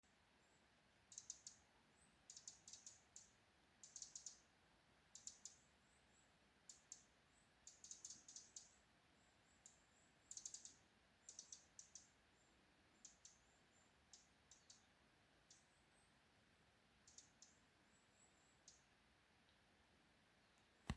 крапивник, Troglodytes troglodytes
Administratīvā teritorijaSaldus novads
СтатусСлышен голос, крики